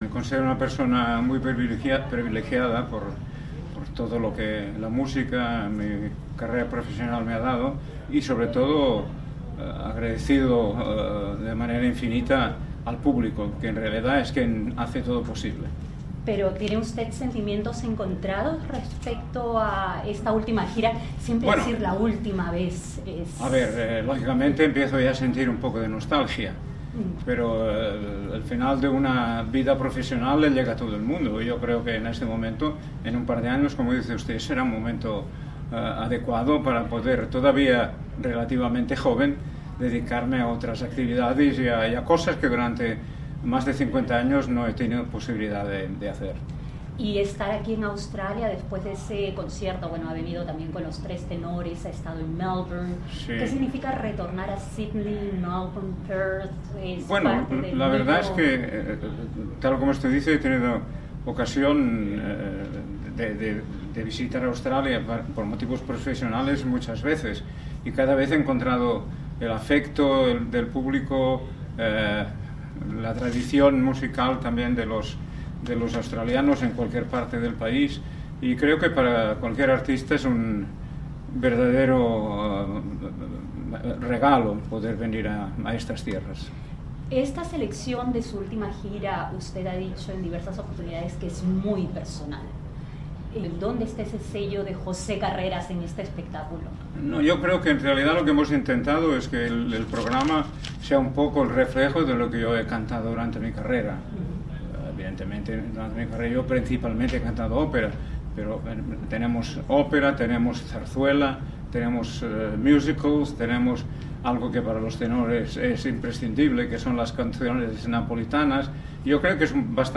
De chaqueta azul y corbata gris, cabellos del mismo tono, Carreras no tutueó en ningún momento mientras hablaba saboreando cada una de las palabras, con cortesía, atendiendo las preguntas de sus interlocutores, aunque sonrió y bromeó, primero, durante la entrevista con SBS y después, con la prensa australiana.